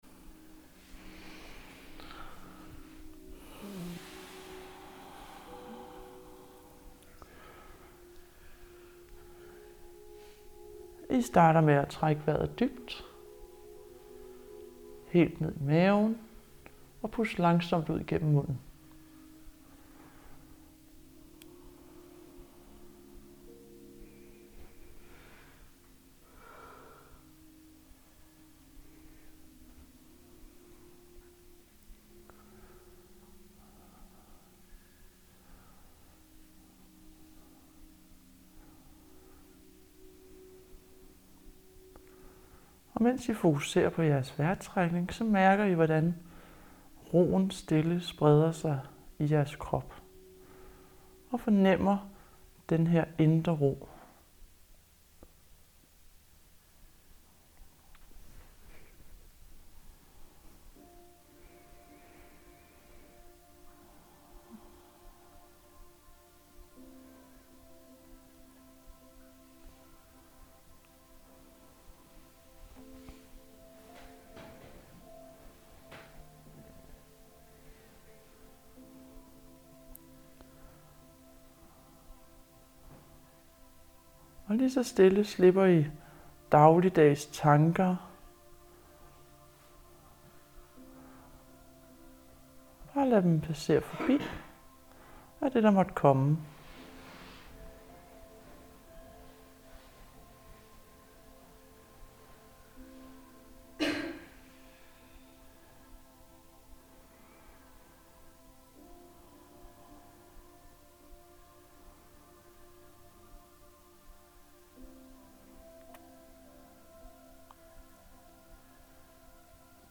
Kropsscanning med musik (lidt snoken) :)
Denne kropsscanning er god til at skabe balance i jeres krop, samt at musikken i baggrunden kan være med til at give mere ro:)